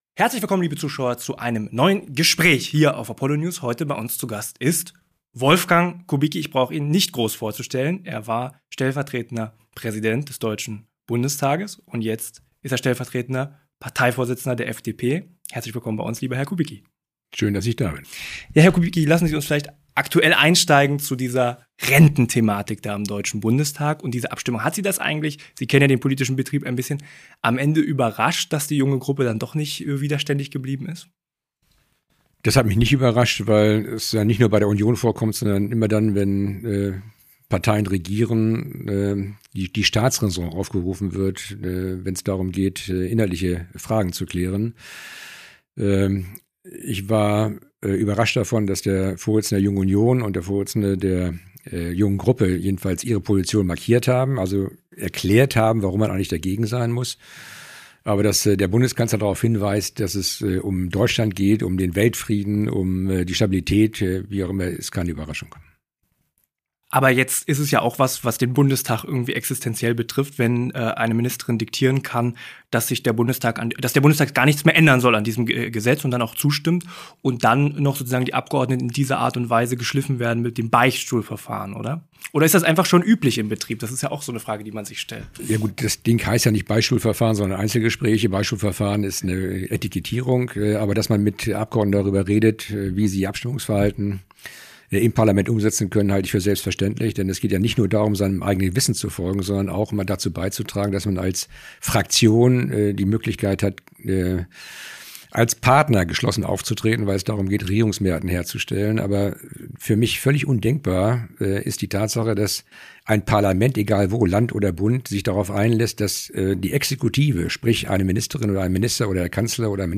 Im Gespräch mit Apollo News bilanziert Wolfgang Kubicki die Arbeit der Bundesregierung: Dass Merz nach sechs Monaten unbeliebter ist als Olaf Scholz nach drei Jahren, sei „schon eine Leistung“. Außerdem geht es um die Fehler und die Zukunftspläne seiner FDP.